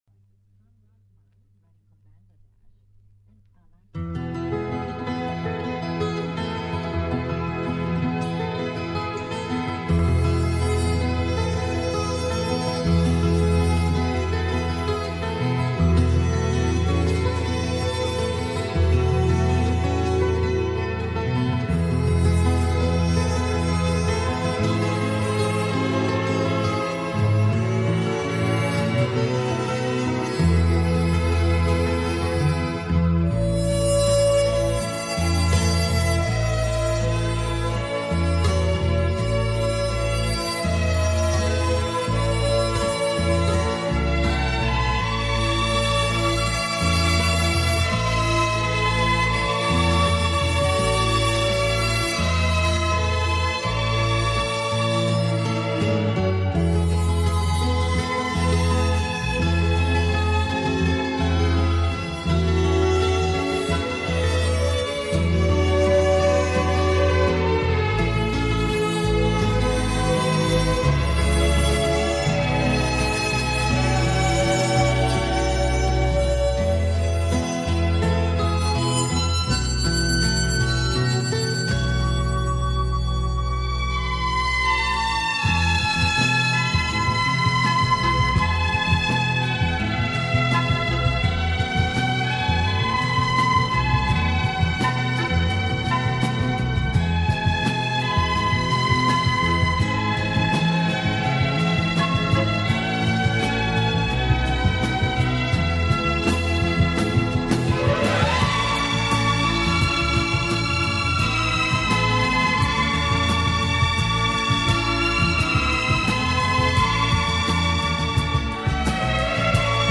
Genre:Soul/ Funk /RnB